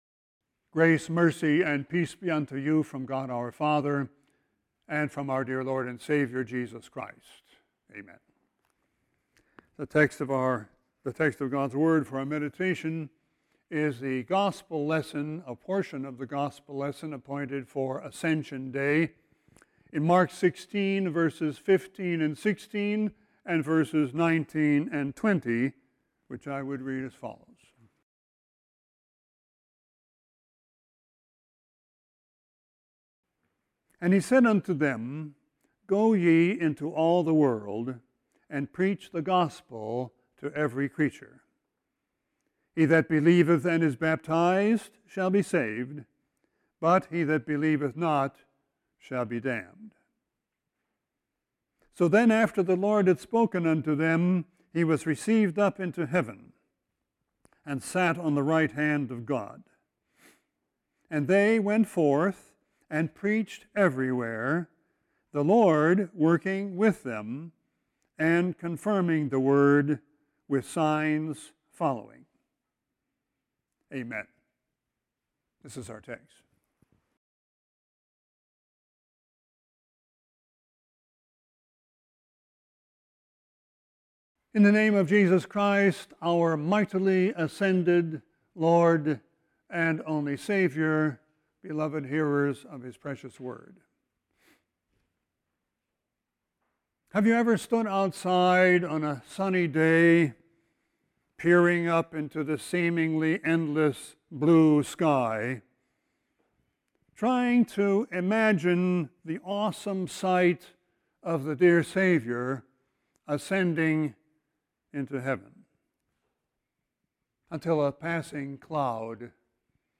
Sermon 6-2-19.mp3